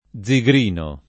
+zigr&no] — com. soprattutto il part. pass. zigrinato, con valore di aggettivo (anche sagrinato, ma meno com., e solo nei sign. propri, riferito a pelle, tela, carta e sim.)